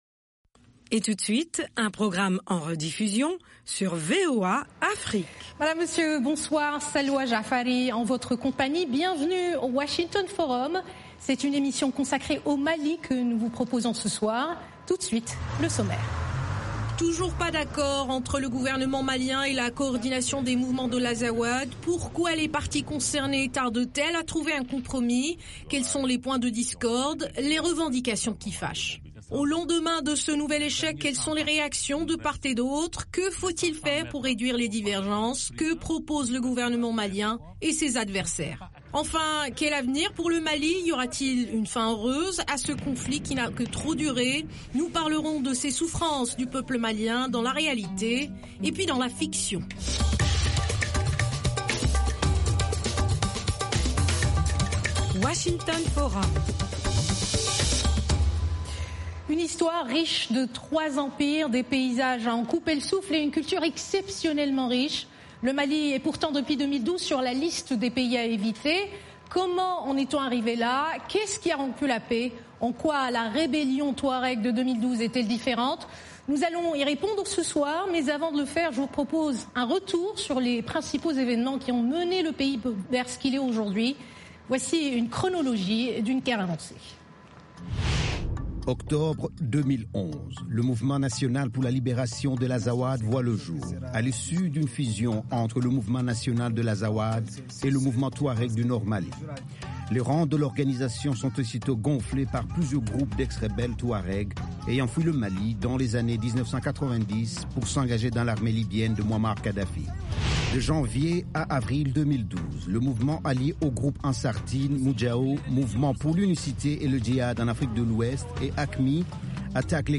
émission interactive en Bambara diffusée en direct depuis Washington, DC